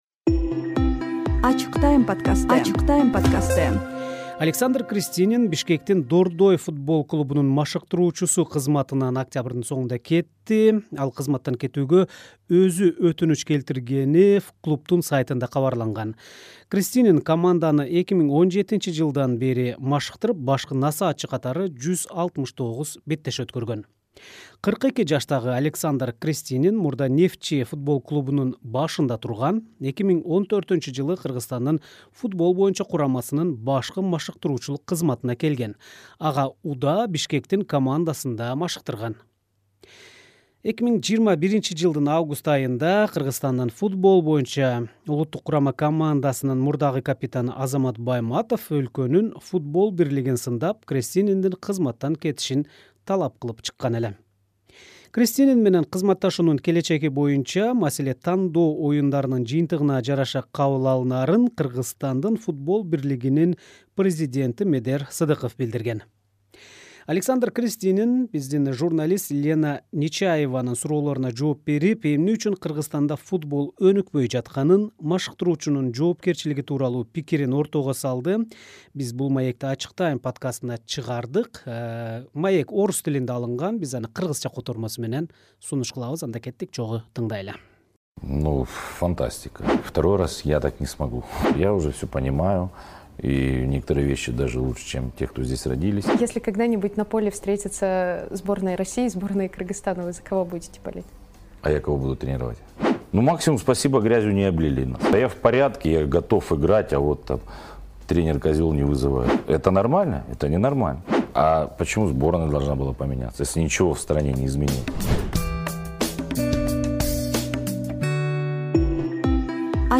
маектешебиз. Ал эмне үчүн Кыргызстанда футбол өнүкпөй жатканын, машыктыруучунун жоопкерчилиги тууралуу пикирин ортого салды.